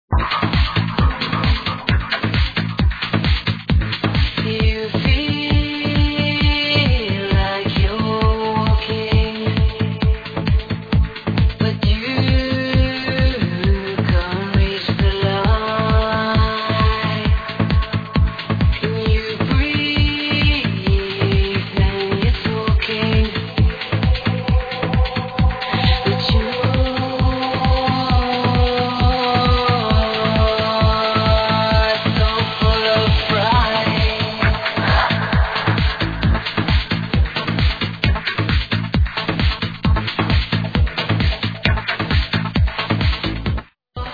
Vocaly Chooon ID?!